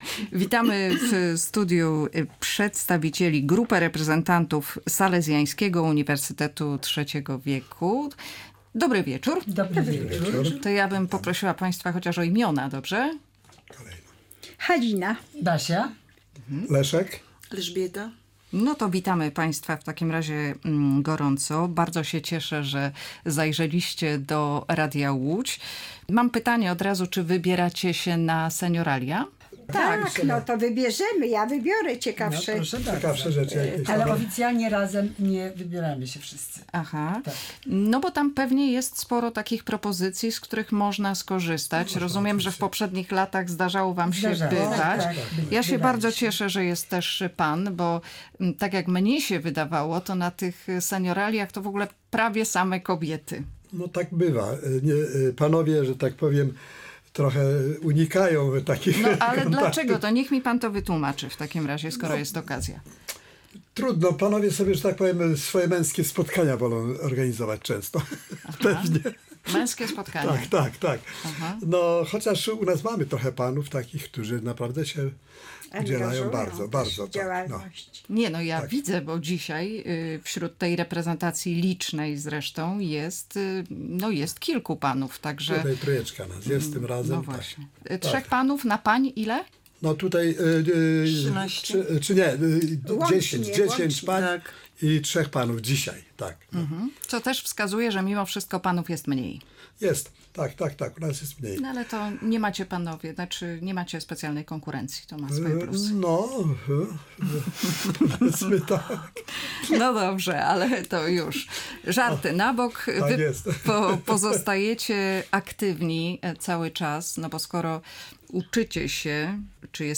Życie seniora może być barwne i pełne twórczych wyzwań. O wspólnych działaniach opowiadali w Radiu Łódź przedstawiciele Salezjańskiego Uniwersytetu Trzeciego Wieku.